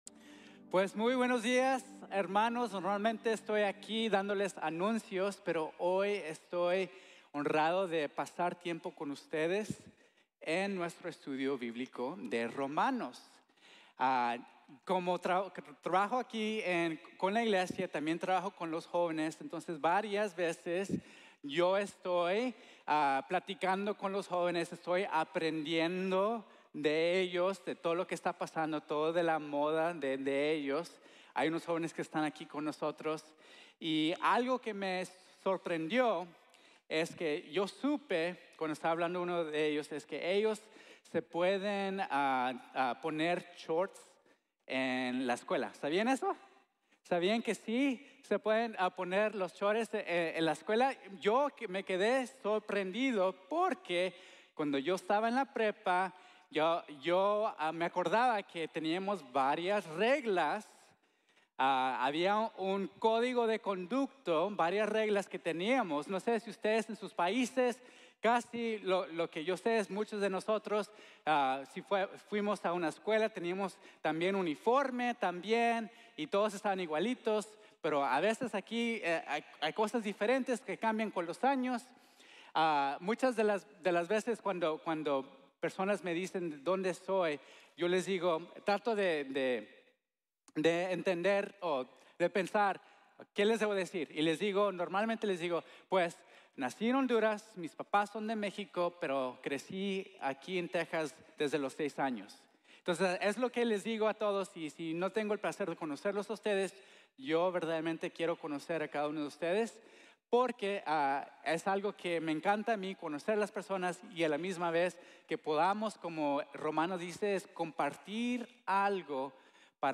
Libres de la Ley | Sermon | Grace Bible Church